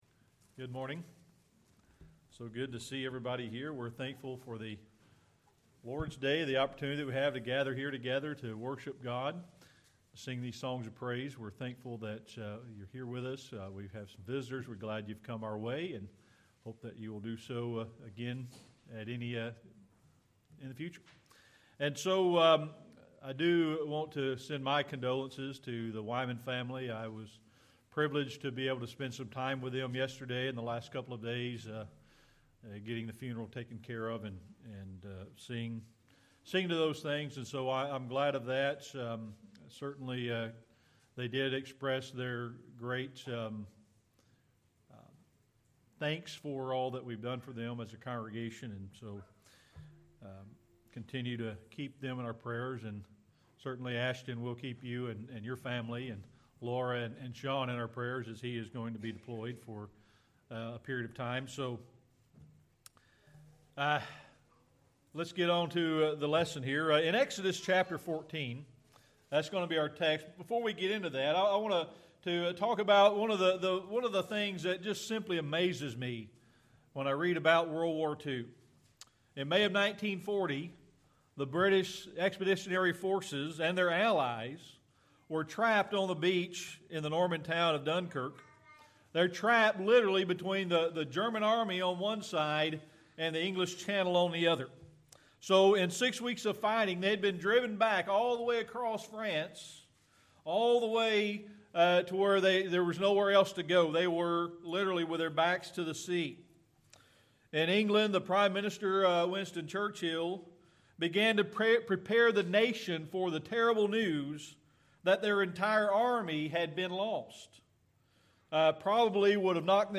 Series: Sermon Archives
Service Type: Sunday Morning Worship